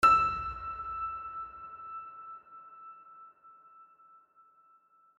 piano-sounds-dev
piano-sounds-dev / HardPiano / e5.mp3